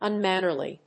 音節un・man・ner・ly 発音記号・読み方
/`ʌnmˈænɚli(米国英語)/